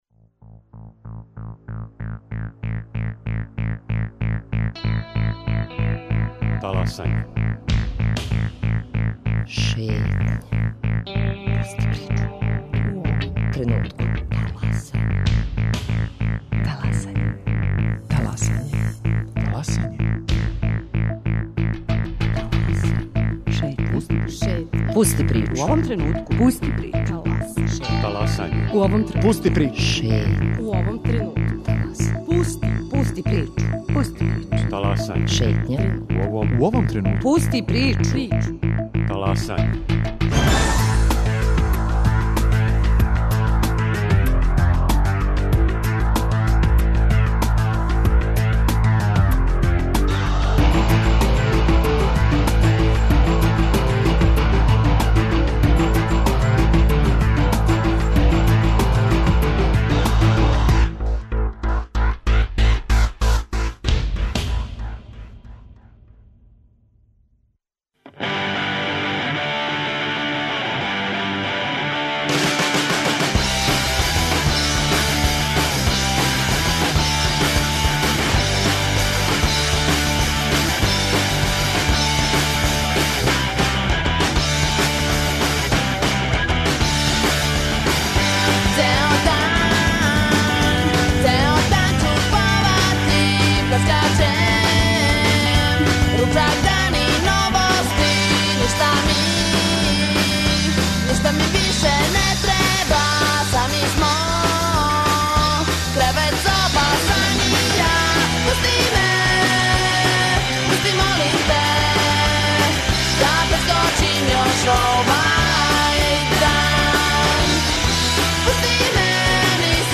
Гости: Тамара Лукшић Орландић, заштитница права деце